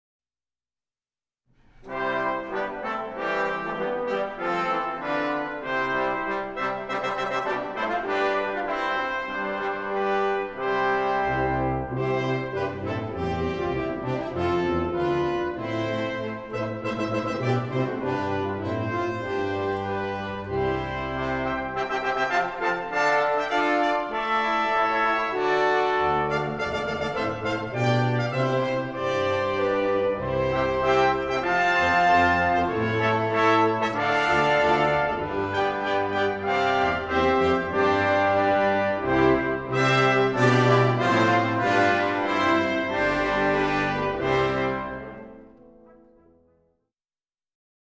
Canzona features 2 antiphonal brass choirs